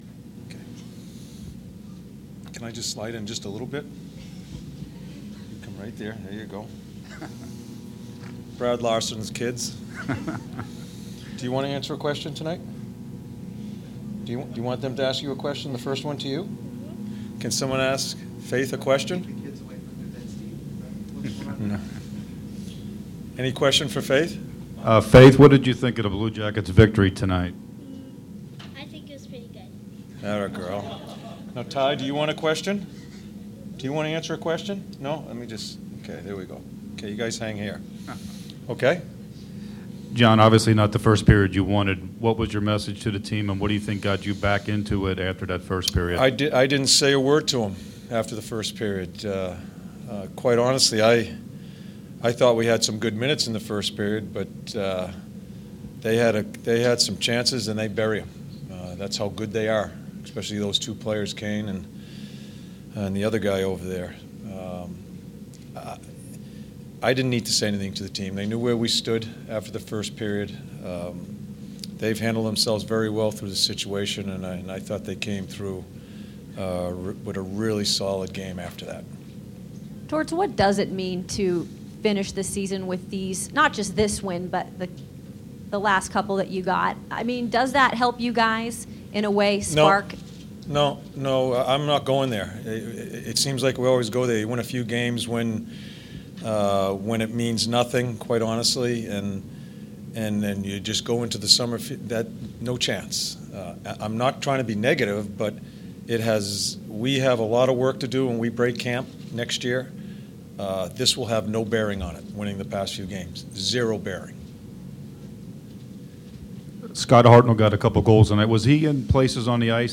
Head Coach John Tortorella addresses media with a few special guests following the teams final game of the 2015-2016 season